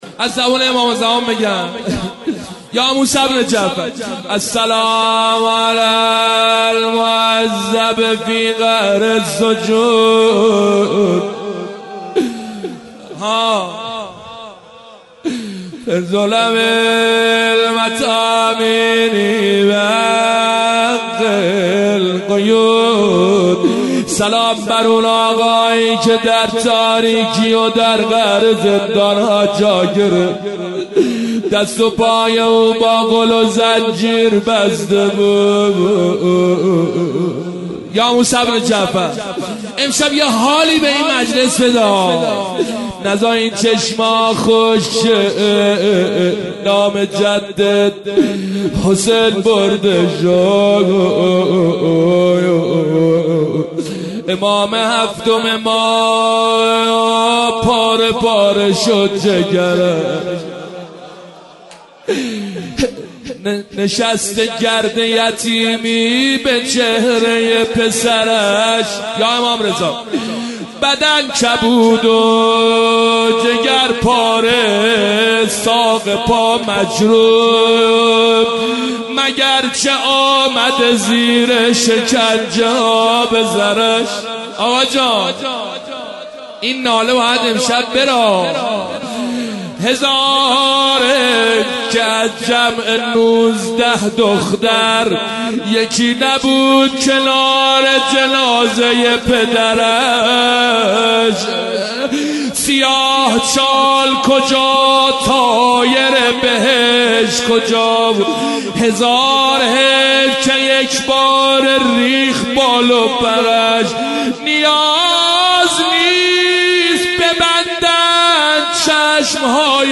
مداحی
به مناسبت شهادت امام موسی کاظم(ع)